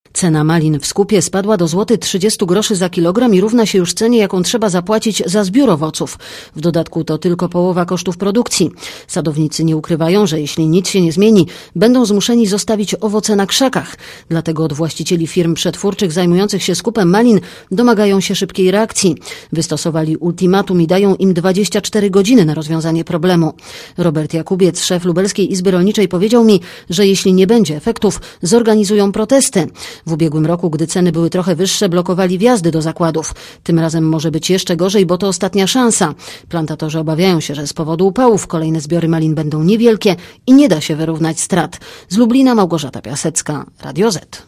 osłuchaj relacji